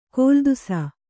♪ kōldusa